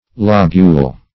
Meaning of lobule. lobule synonyms, pronunciation, spelling and more from Free Dictionary.